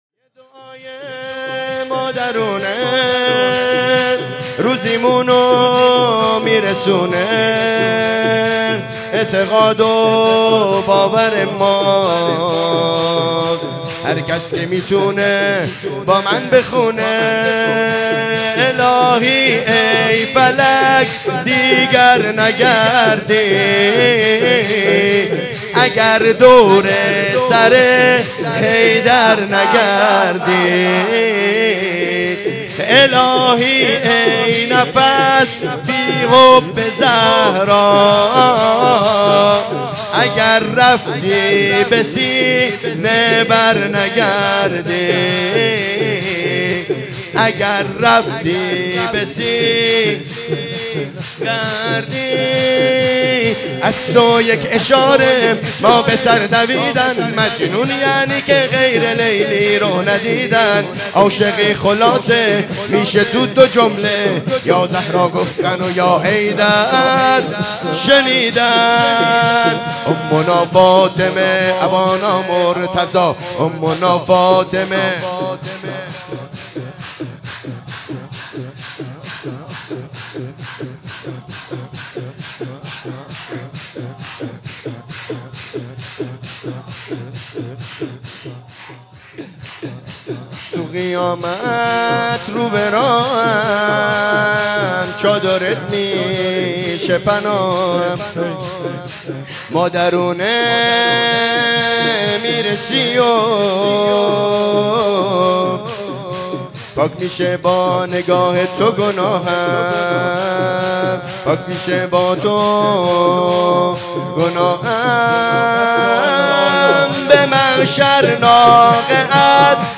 جشن ولادت حضرت زهرا سلام الله